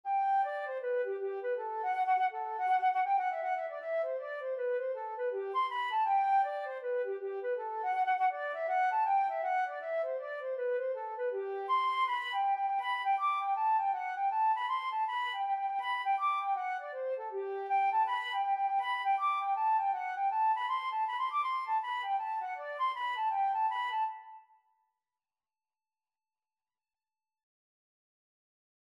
Free Sheet music for Flute
G major (Sounding Pitch) (View more G major Music for Flute )
4/4 (View more 4/4 Music)
Flute  (View more Easy Flute Music)
Traditional (View more Traditional Flute Music)
Irish